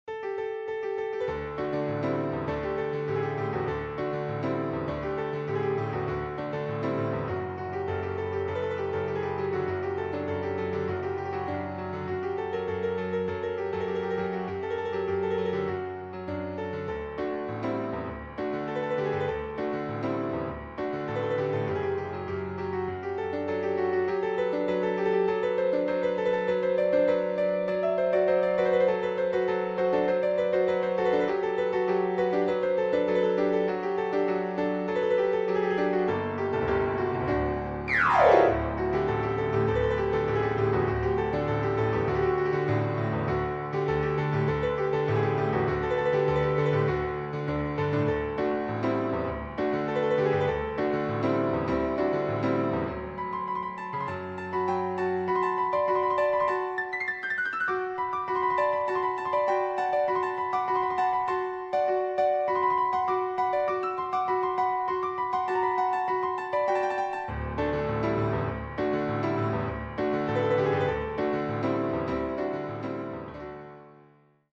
Müəllif: Azərbaycan Xalq Musiqisi